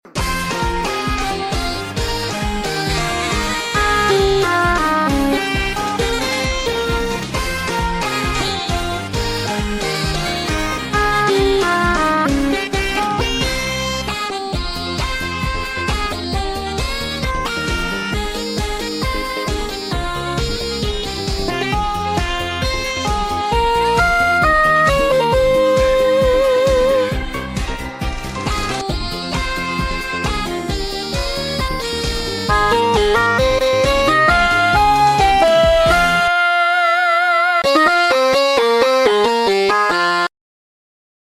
Cover - Demo version for now.